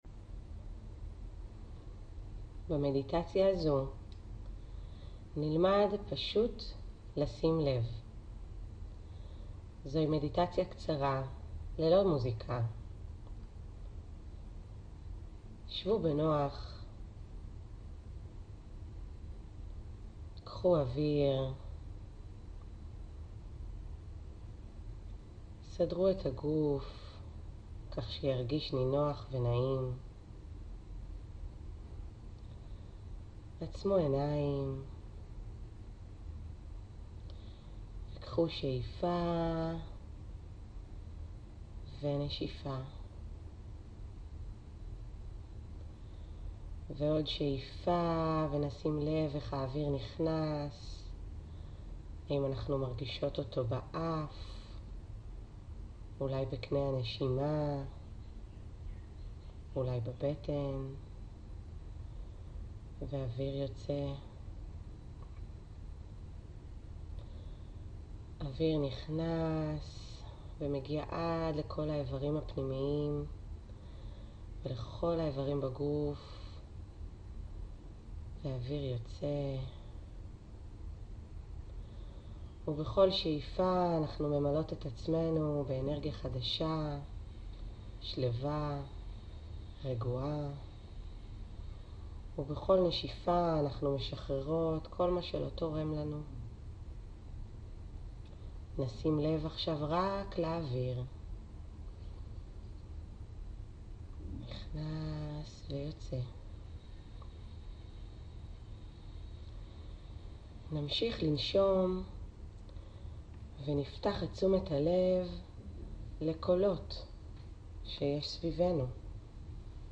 מדיטציית מיינדפולנס בסיסית – 8:27 דק'
מיינדפולנס+בסיסי+מחוברות.mp3